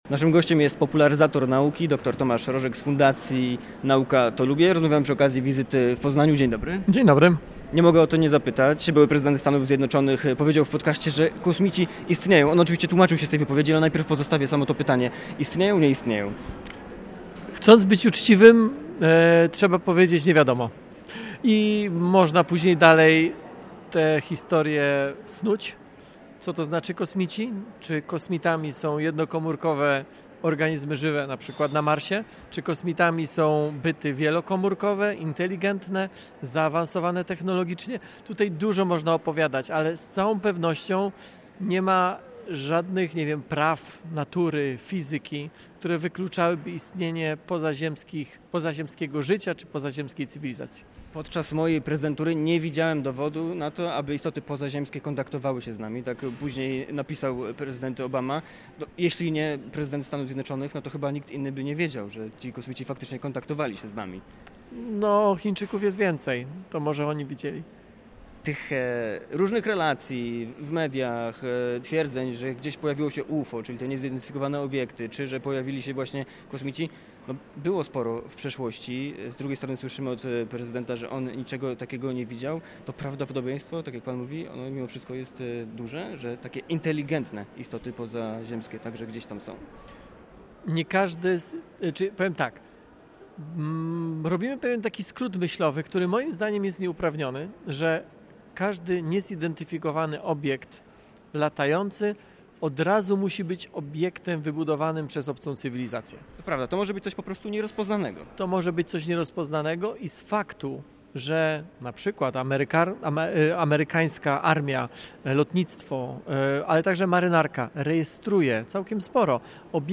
Twórca serwisu Nauka To Lubię skomentował w „Popołudniowej rozmowie” Radia Poznań możliwość utworzenia w stolicy Wielkopolski jednej z pięciu unijnych instalacji poświęconych sztucznej inteligencji.